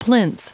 Prononciation du mot plinth en anglais (fichier audio)
Prononciation du mot : plinth